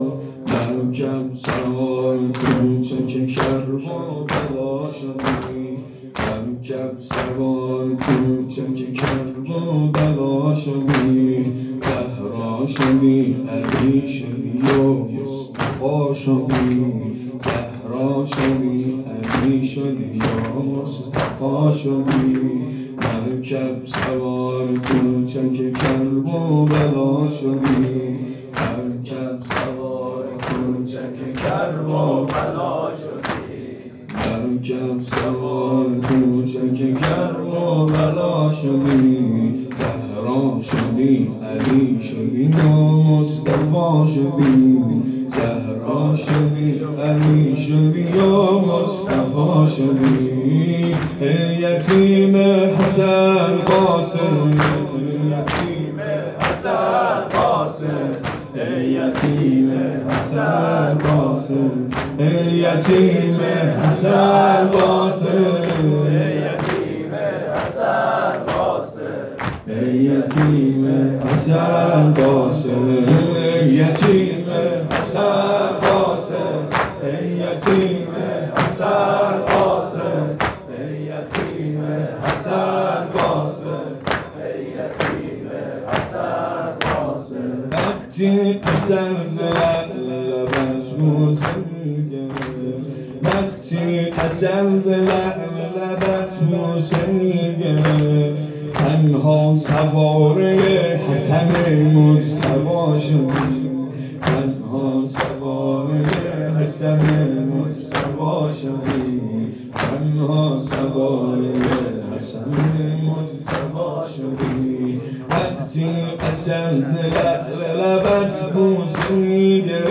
شب ششم محرم۹۷ هیئت صادقیون(ع)
2-زمینه-مرکب-سوار-کوچک-کرب-وبلا-شدی.mp3